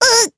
Lewsia_A-Vox_Damage_jp_02.wav